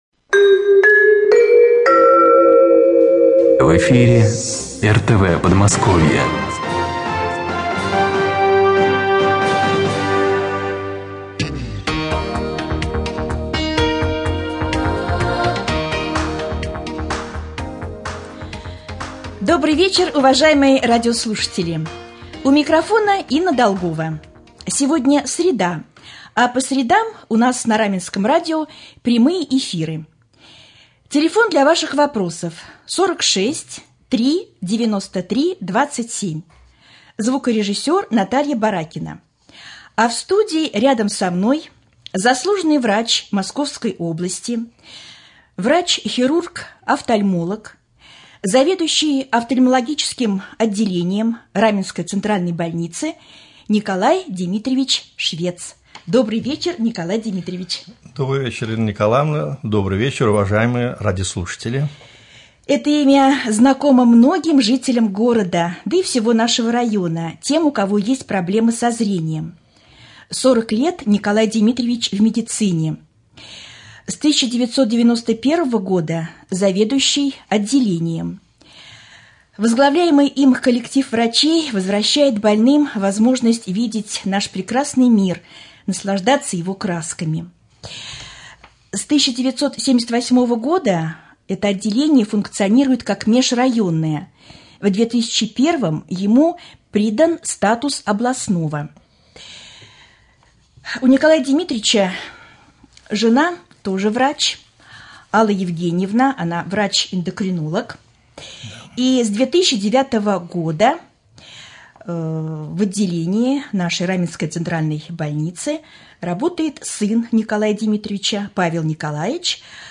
прямой-эфир.mp3